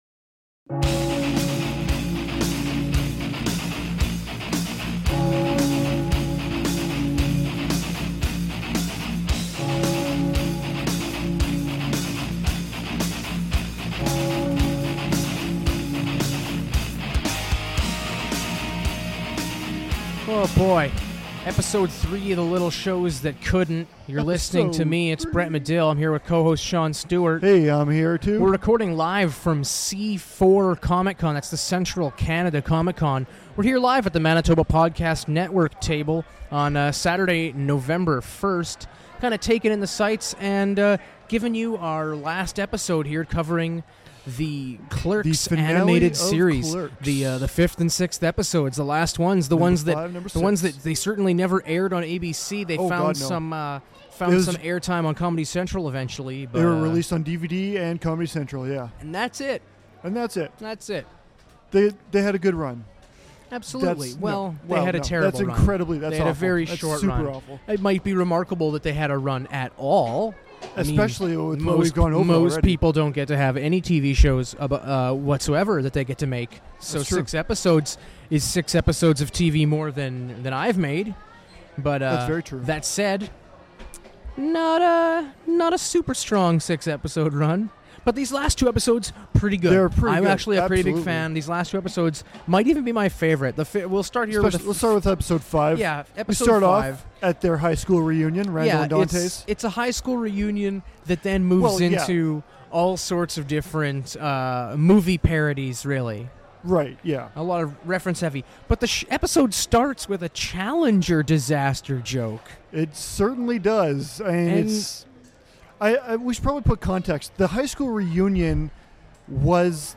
Clerks: The Animated Series Finale LIVE at Comic Con
The fellas finish off Clerks The Animated Series live at Winnipeg’s Central Canada Comic Con! Once they’re done examining the series; the fellas then discuss which character, once removed, would make the show better, along with other games!